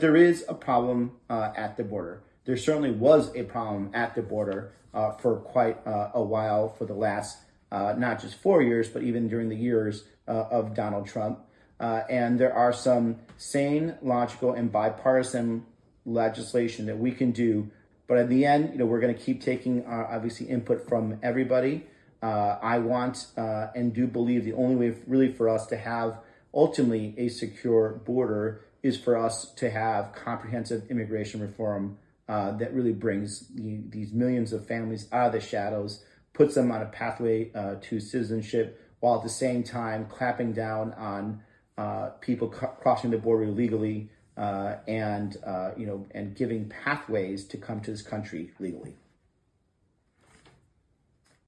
PHOENIX – Last night, Senator Ruben Gallego (D-AZ) spoke to over 3,000 Arizonans during his first telephone town hall.